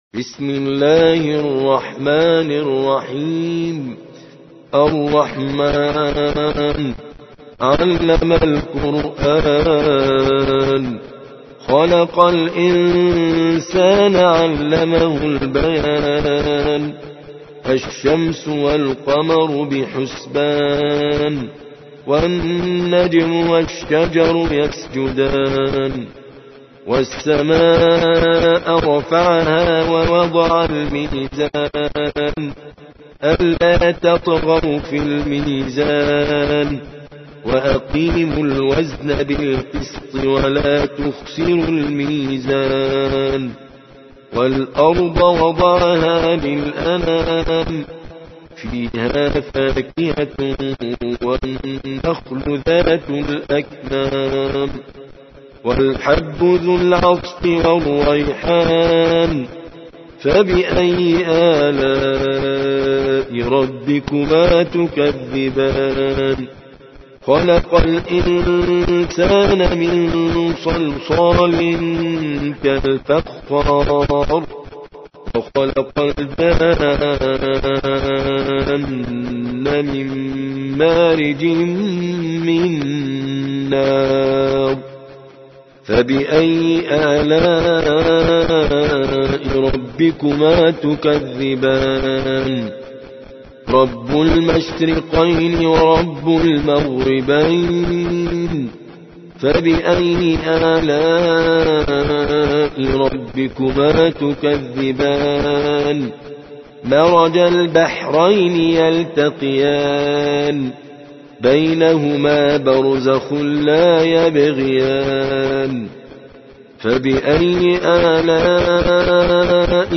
55. سورة الرحمن / القارئ